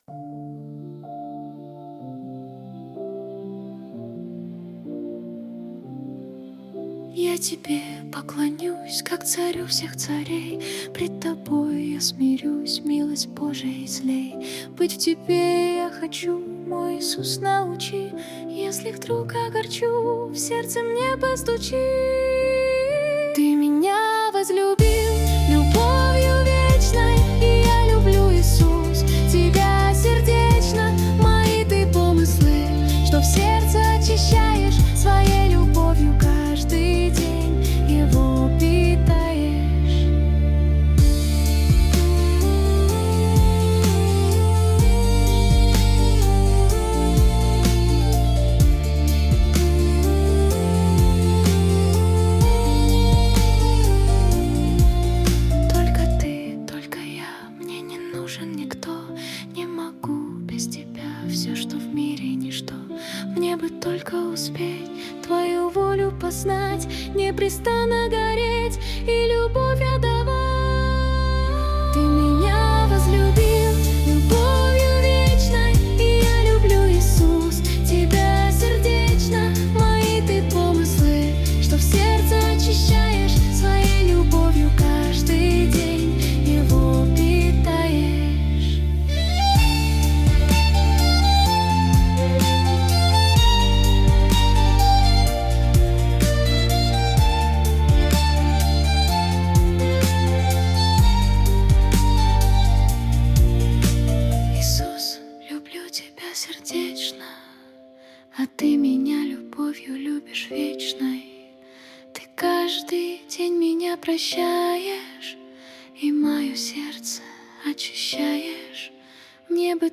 песня ai
182 просмотра 890 прослушиваний 45 скачиваний BPM: 128